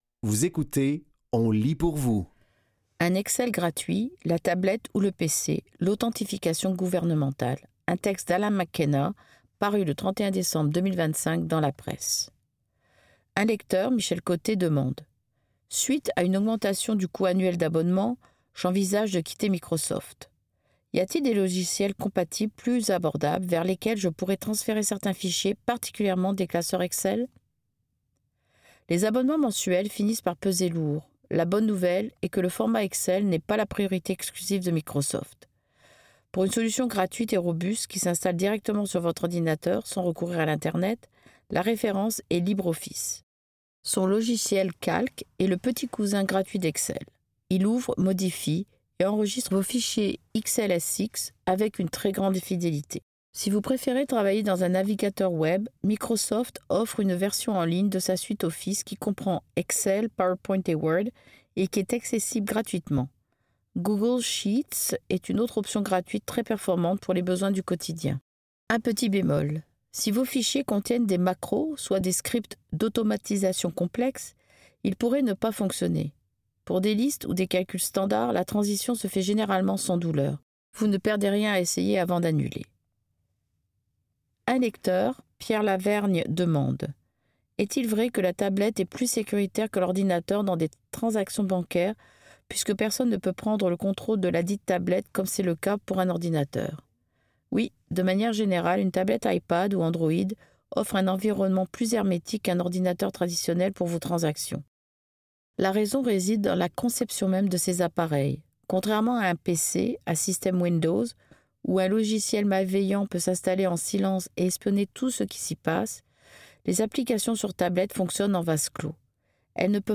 Dans cet épisode de On lit pour vous, nous vous offrons une sélection de textes tirés des médias suivants: La Presse, Le Devoir. Au programme: Un Excel gratuit, la tablette ou le PC, l’authentification gouvernementale.